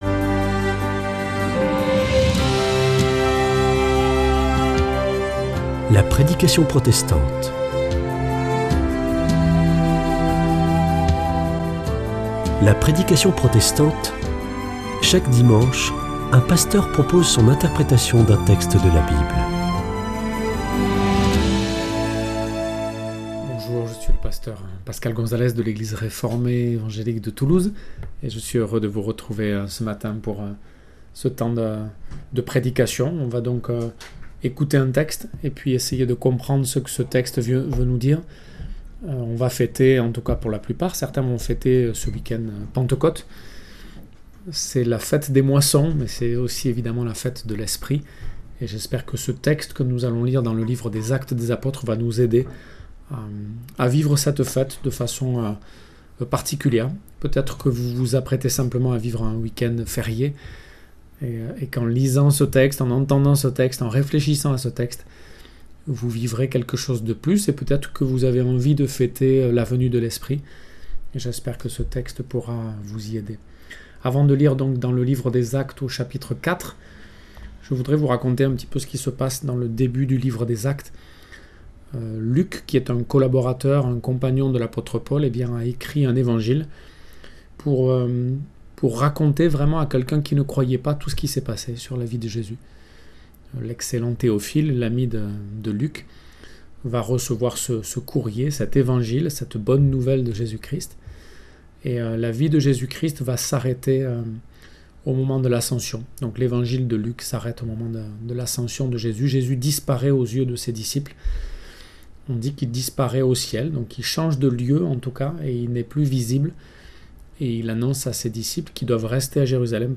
Est-ce que l’Esprit produit en nous les mêmes fruits ? Prédication sur le chapitre 4 du livre des Actes des apôtres.